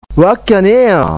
医療人のための群馬弁講座；慣用句